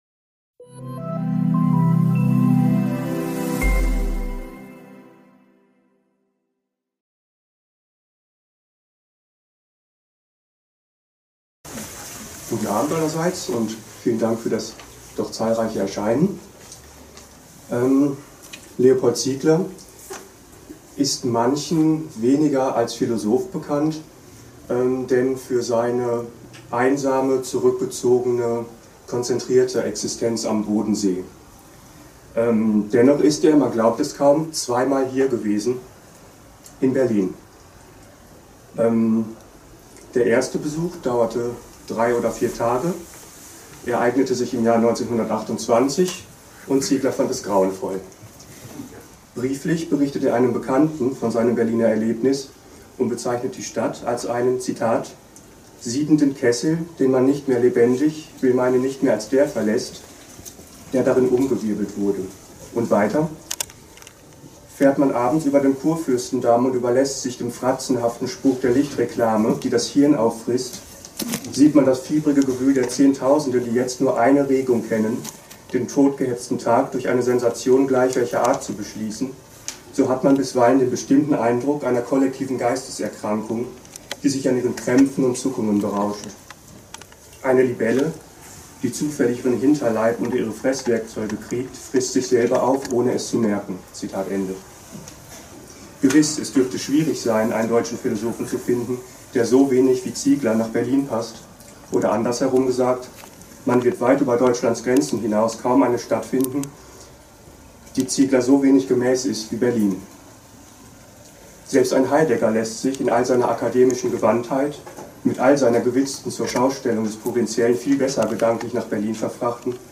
am 7. Juli 2016 in der Bibliothek des Konservatismus